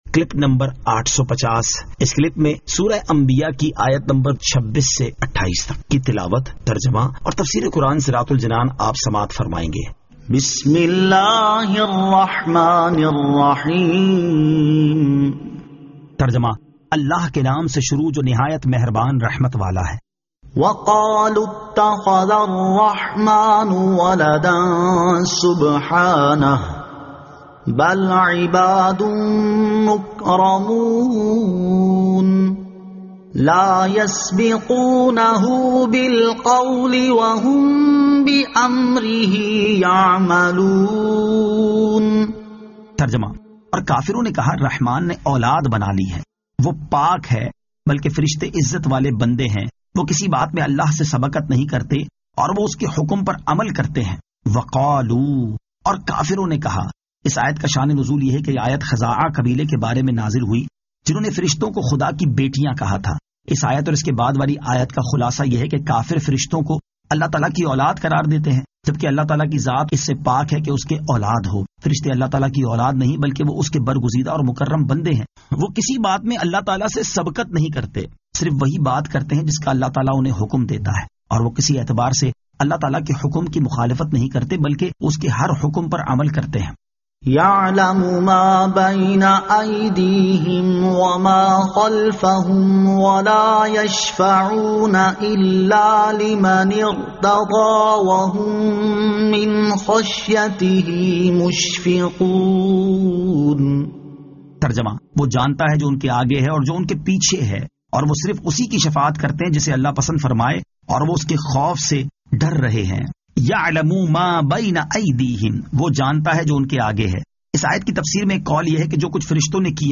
Surah Al-Anbiya 26 To 28 Tilawat , Tarjama , Tafseer